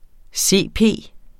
CP forkortelse Udtale [ ˈseˀˈpeˀ ] Betydninger = cerebral parese For hver 1.000 børn, som fødes, er der to, som senere viser sig at have cerebral parese (CP) UgesLæger2014 Ugeskrift for Læger (blad), 2014.